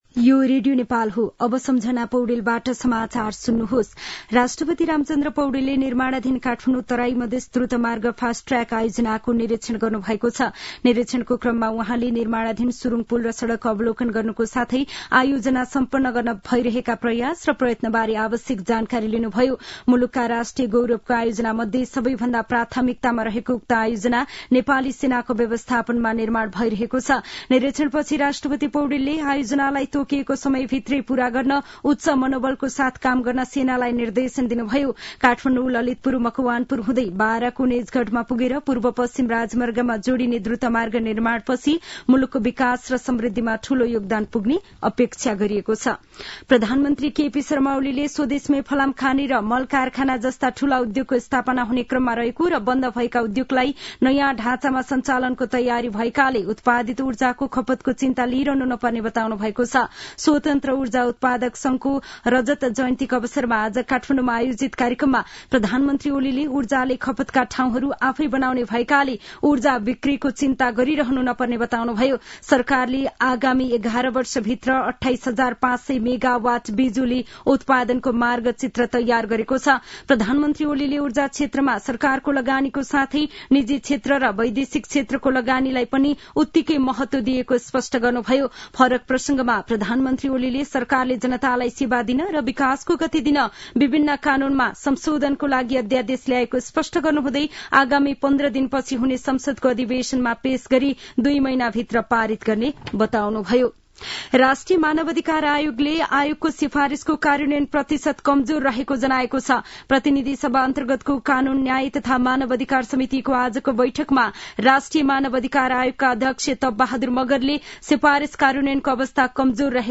साँझ ५ बजेको नेपाली समाचार : ५ माघ , २०८१